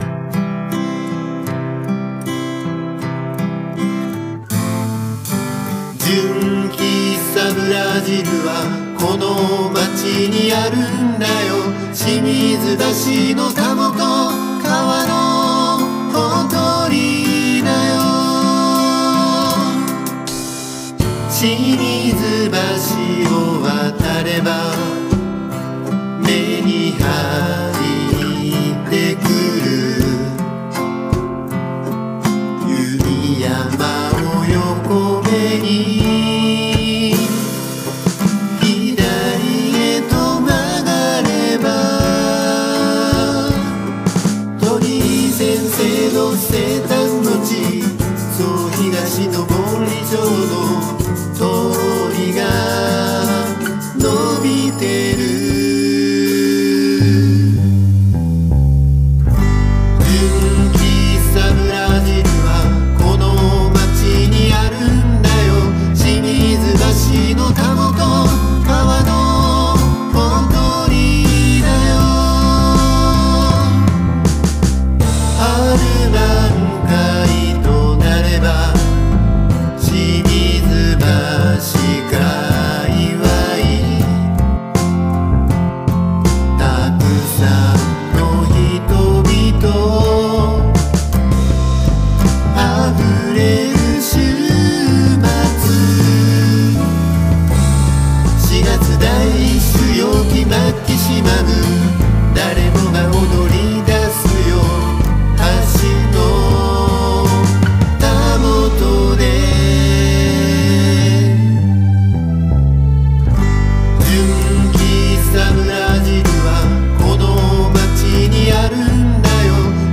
ドラム：Steven Slate Drums 5.5
アコギ：Epiphone EJ-160E